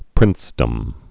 (prĭnsdəm)